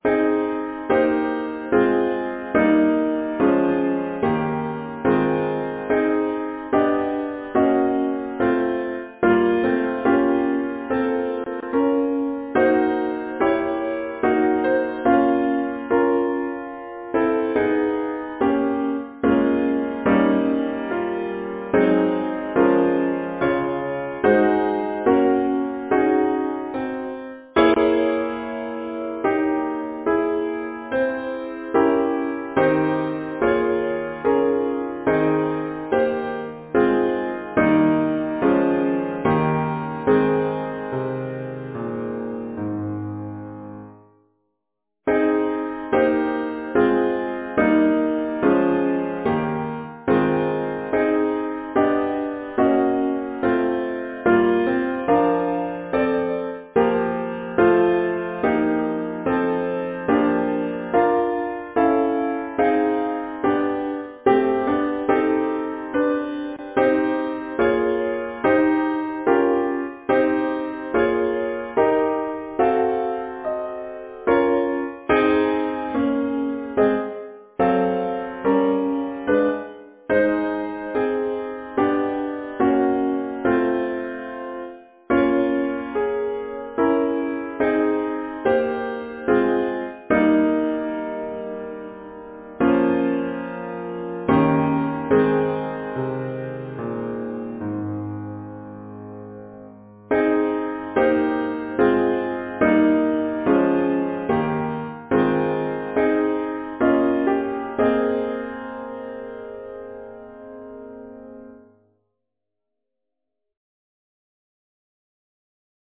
Title: When you sing Composer: Hubert Bath Lyricist: Robert Herrick Number of voices: 4vv Voicing: SATB, some B divisi Genre: Secular, Partsong
Language: English Instruments: A cappella